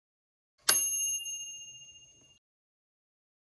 Кружка оказалась на столе